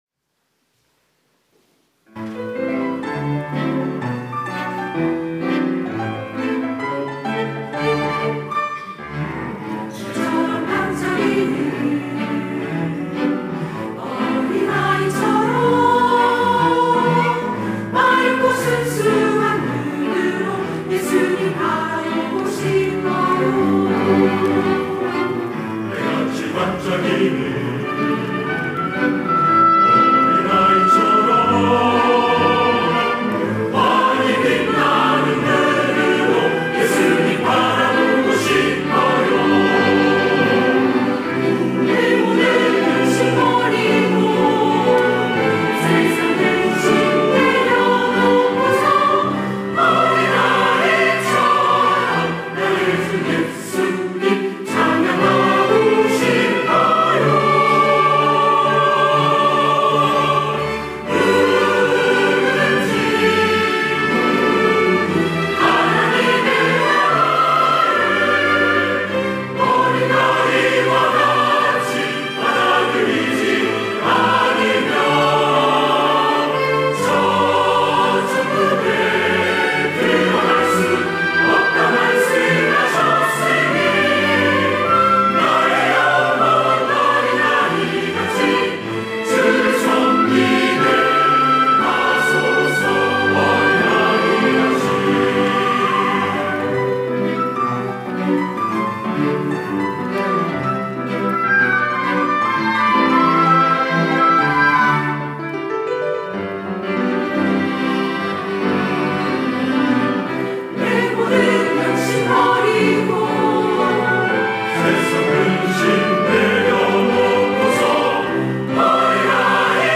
할렐루야(주일2부) - 어린이의 마음
찬양대